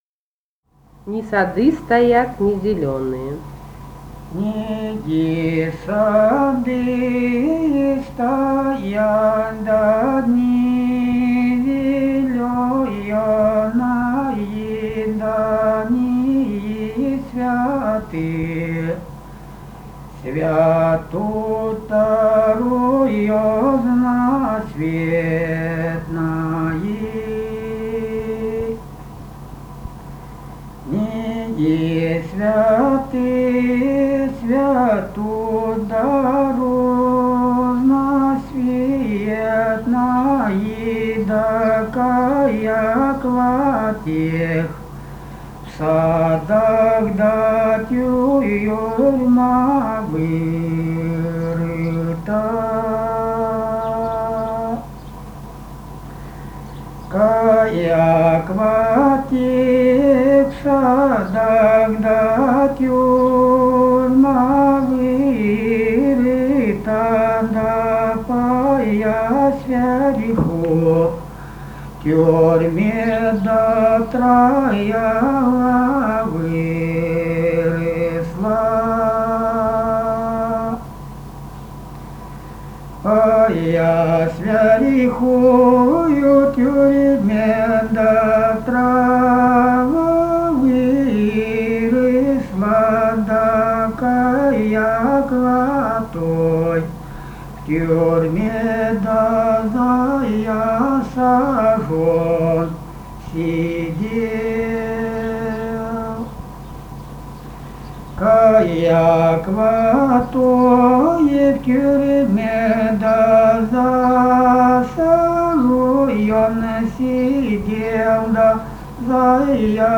полевые материалы
Ставропольский край, с. Бургун-Маджары Левокумского района, 1963 г. И0729-08